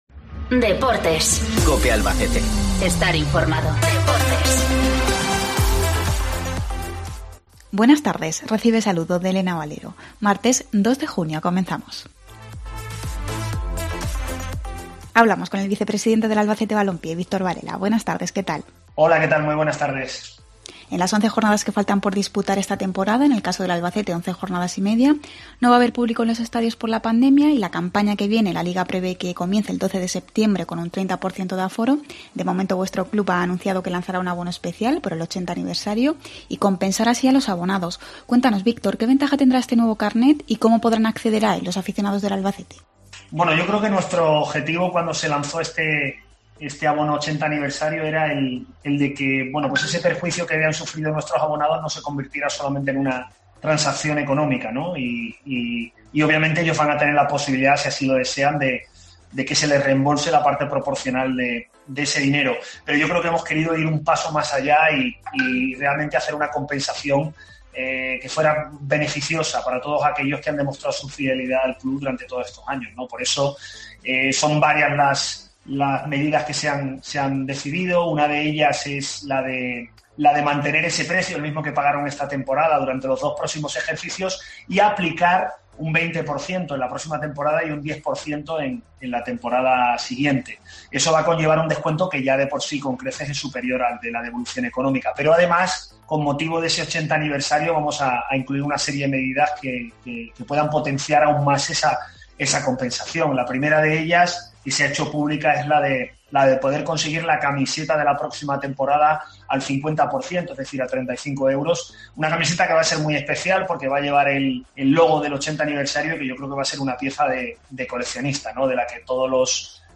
Entrevista Deportes